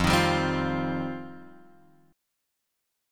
F7sus2 chord {1 x 1 0 1 1} chord